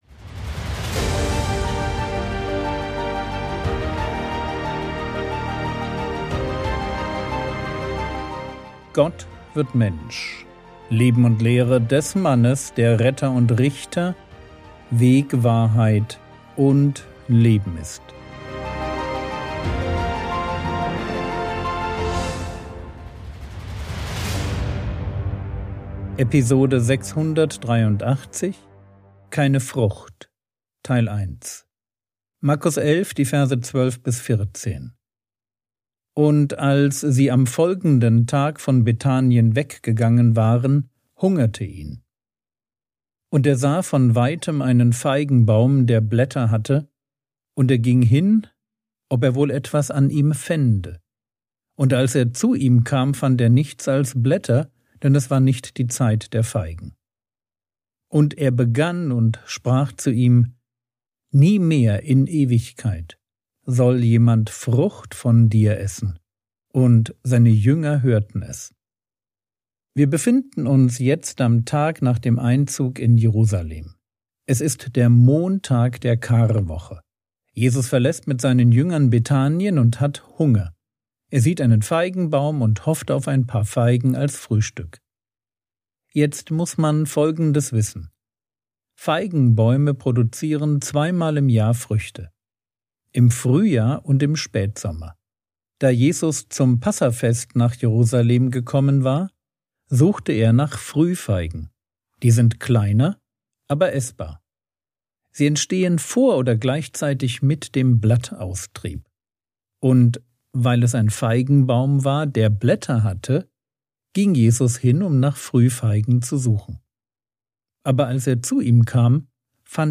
Episode 683 | Jesu Leben und Lehre ~ Frogwords Mini-Predigt Podcast